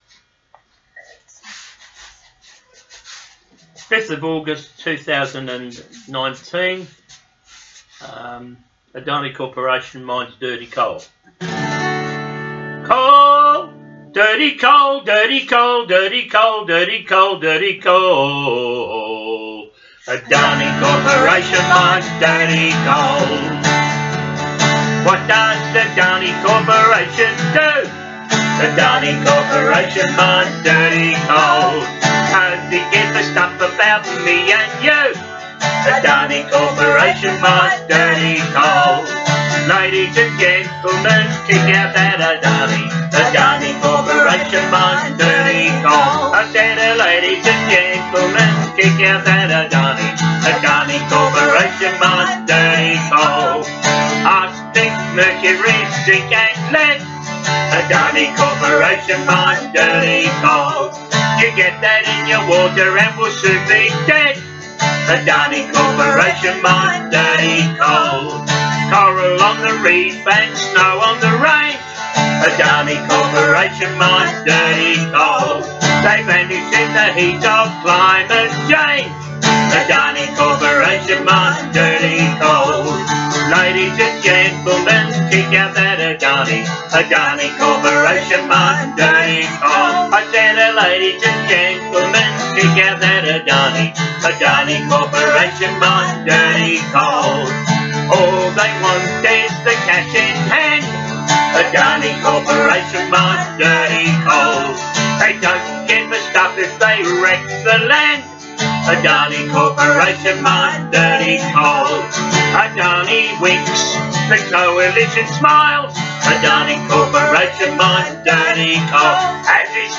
protest song